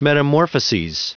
Prononciation du mot metamorphoses en anglais (fichier audio)
Prononciation du mot : metamorphoses